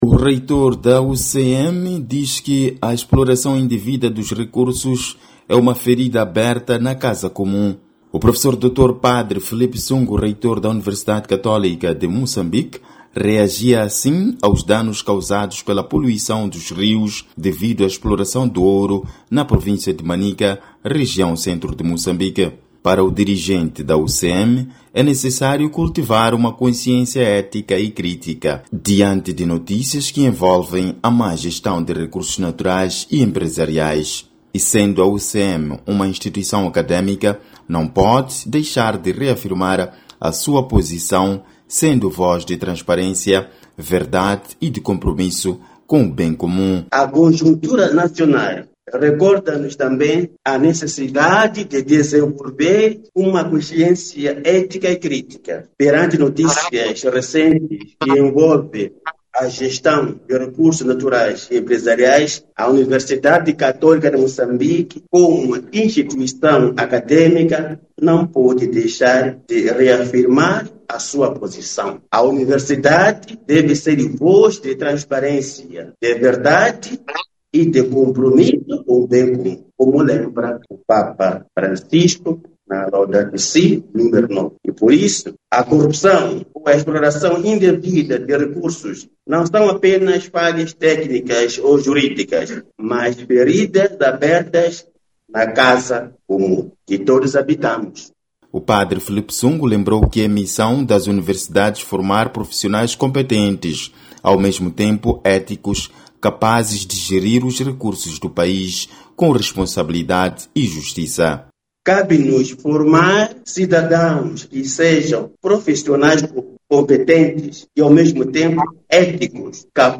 Beira, Moçambique